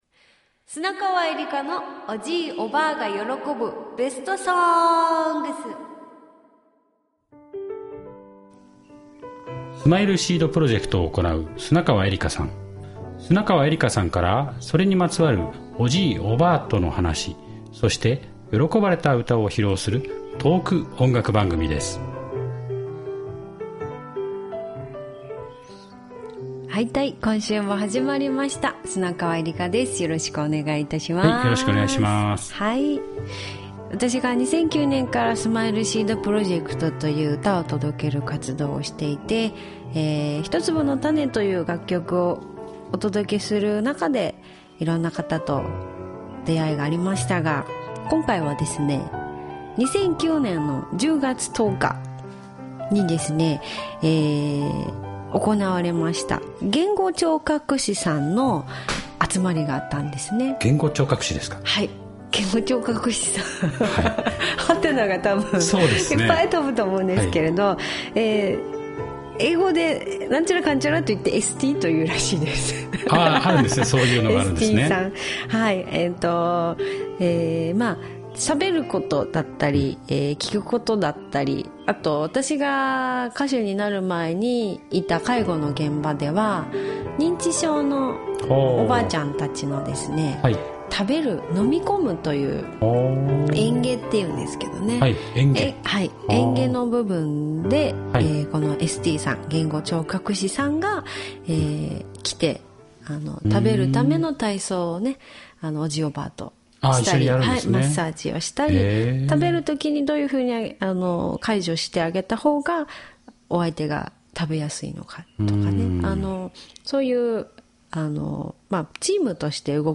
言語聴覚士の皆さんと歌った定番の曲 | 「安里屋ユンタ」 八重山民謡 - ０７月０１日配信
言語聴覚士の皆さんと歌った定番の曲
定番のコールアンドレスポンスの曲と言えばこの歌、「安里屋ユンタ」。
田植歌がもとになっているこの歌のノリの良さとともにお送りします。